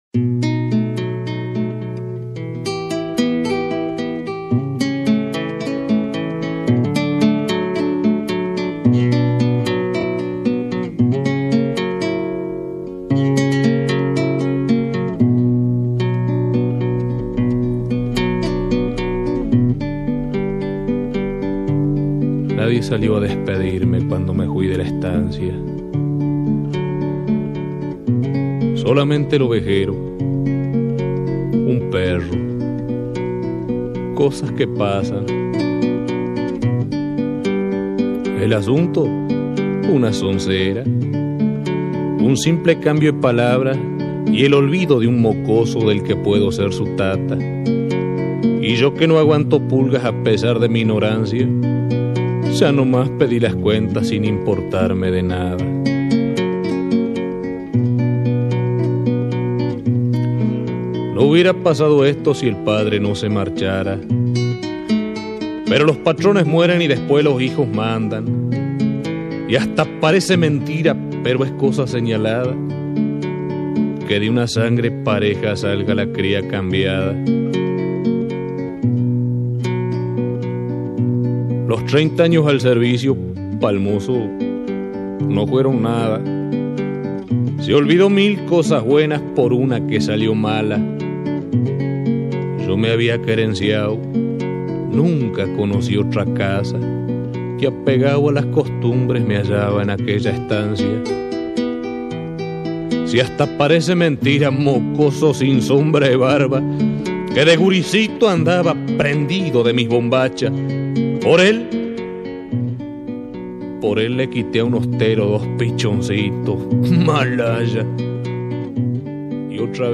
Carpeta: Folklore mp3